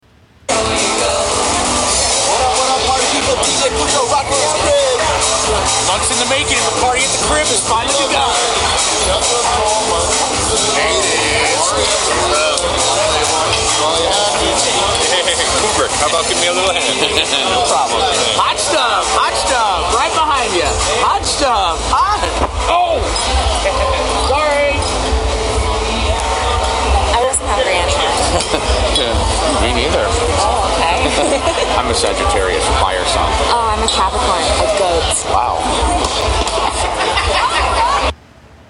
Party Sounds